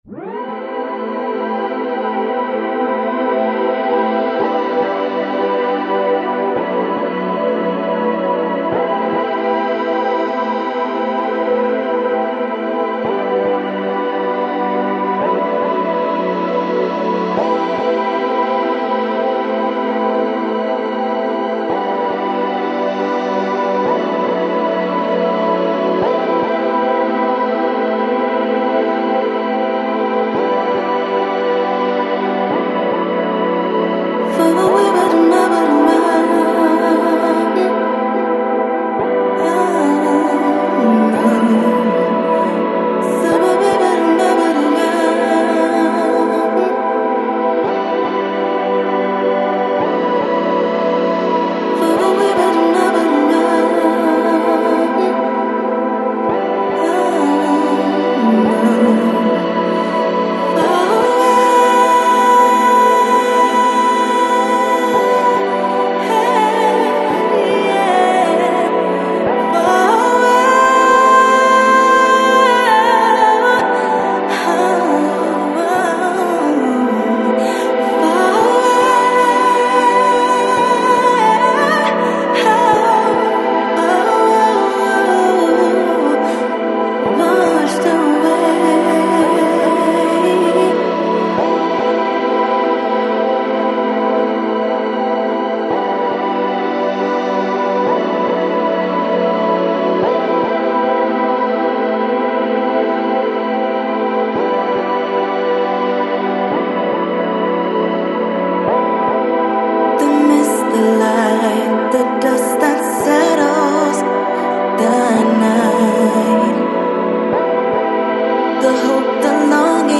Downtempo, Soul, Electronic